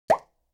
au_effect_element_pop.mp3